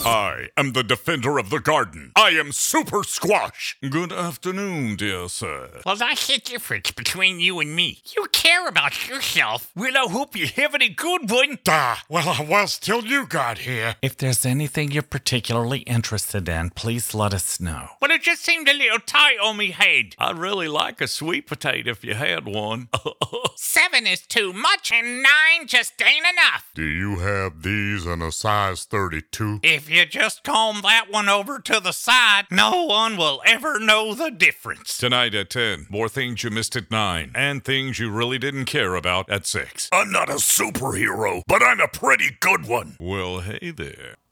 Character, Cartoon and Animation Voice Overs
Adult (30-50)